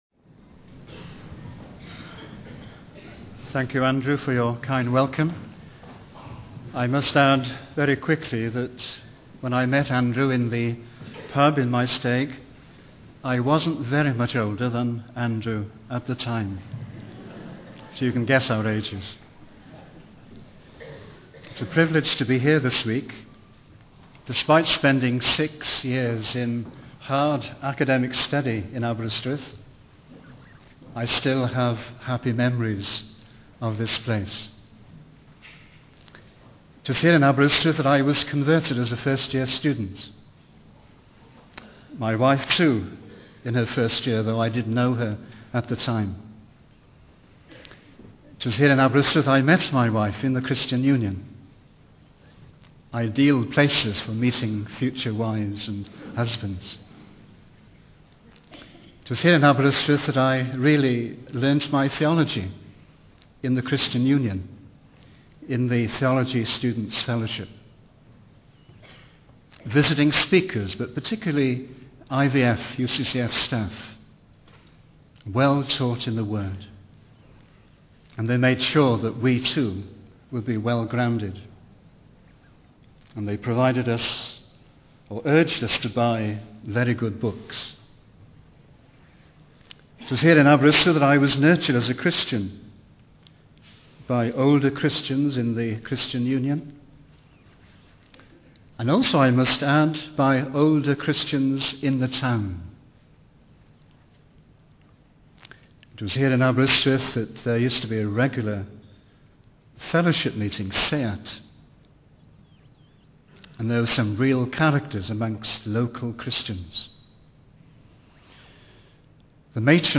In this sermon, the speaker emphasizes the importance of being deeply moved by the truth and the Spirit of God. He highlights the apostle Paul's profound focus on God in his writing, specifically in Ephesians chapter 1.